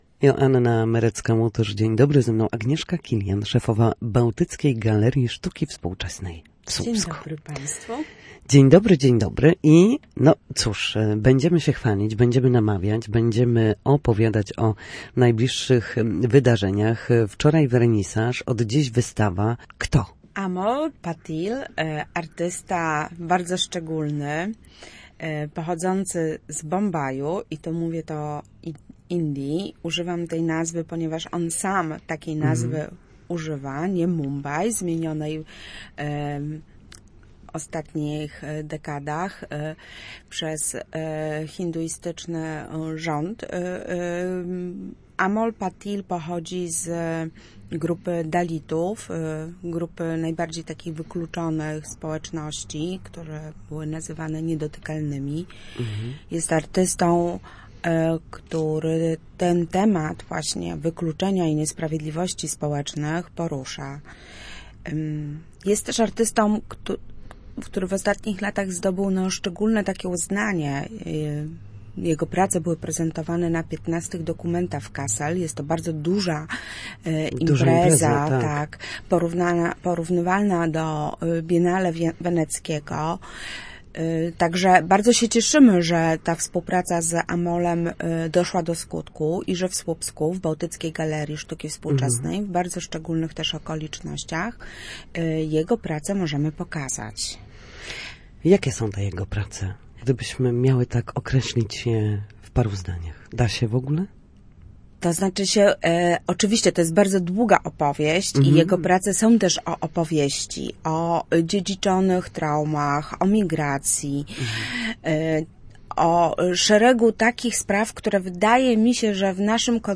Gościem porannego programu Studia Słupsk